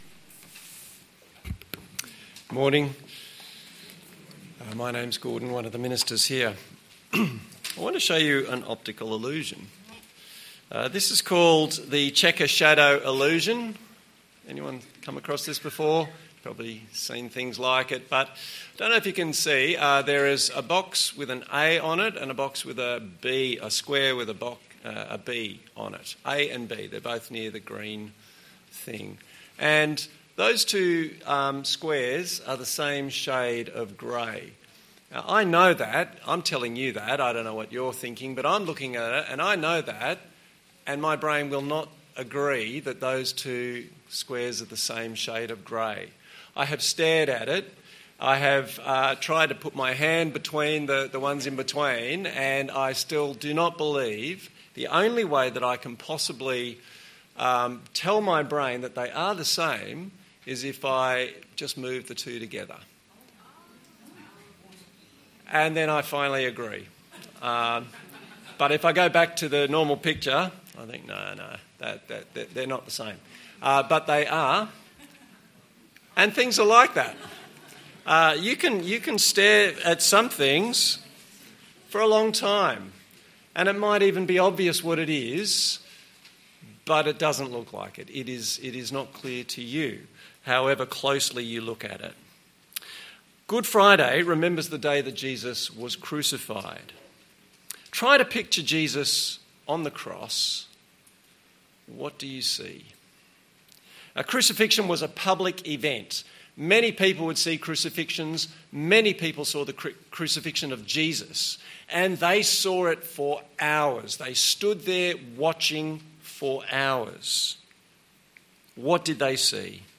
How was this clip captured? Service Type: Good Friday Topics: Easter , forgiveness , salvation « Forward to maturity The 3 R’s of Easter